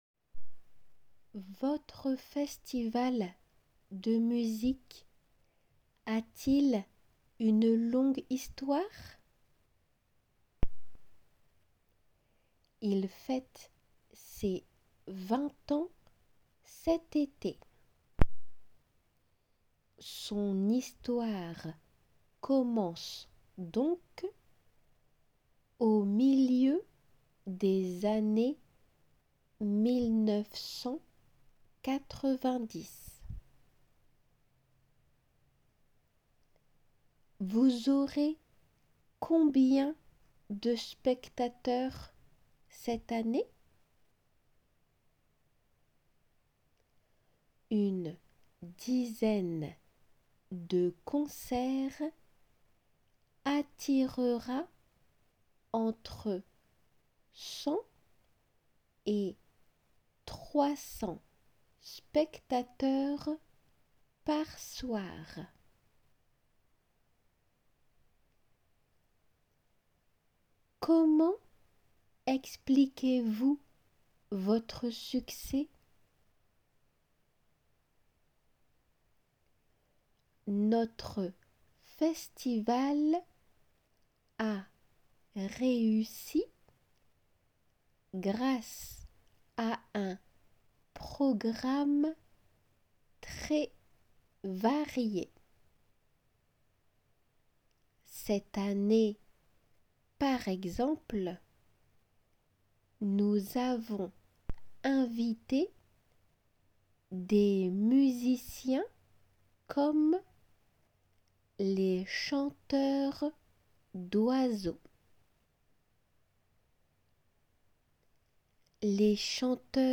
読まれるテキスト
聞きとり用の音声は　5セット　会話体で構成されている傾向。